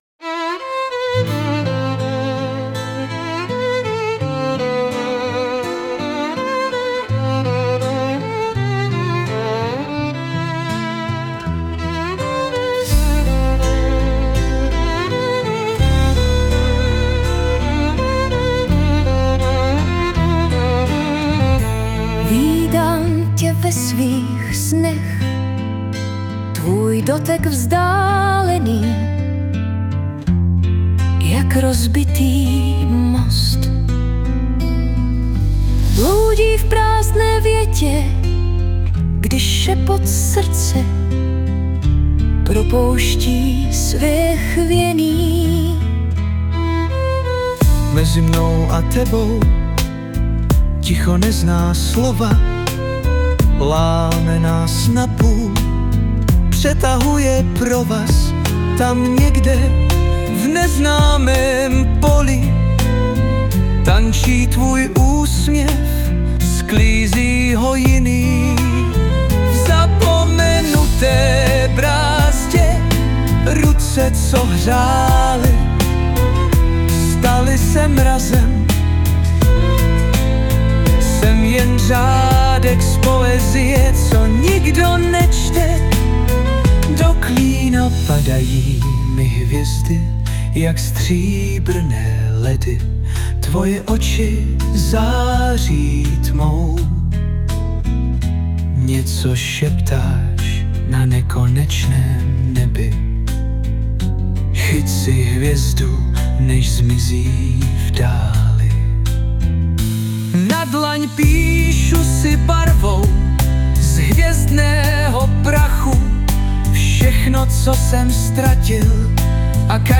2025 & Hudba, zpěv a obrázek: AI
s hudbou jsem někde ve Francii - to je super
plyne nádherně